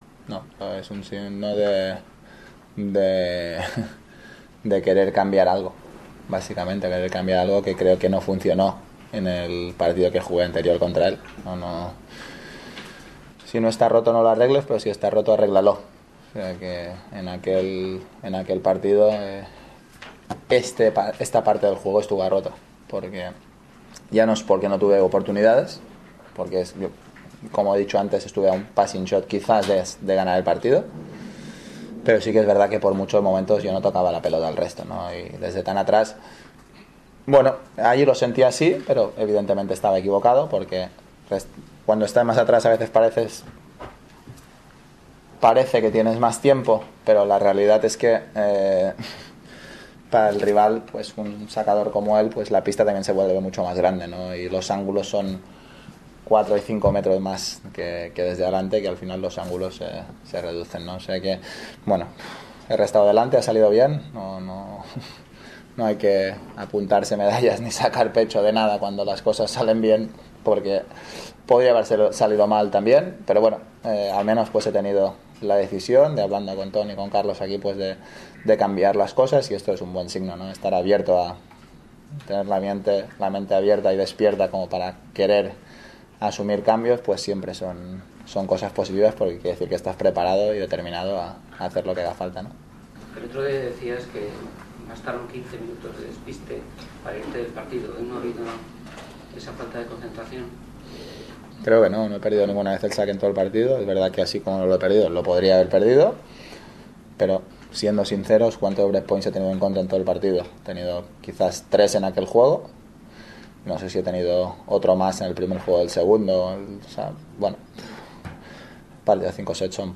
El tenista español, que se prepara para su encuentro mañana en la semifinal del abierto de Australia contra el búlgaro Grigor Dimitrov, habla de su partido contra Milos Raonic (3), que ganó por 6-4, 7-6 y 6-4 (después de 2h 44m) y compara su desempeño en el Abierto de Australia, con su actuación en Brisbane, donde perdió ante Raonic por 4-6, 6-3 y 6-4. Escucha aquí la entrevista: